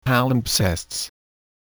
Click to hear the pronunciation of palimpsest.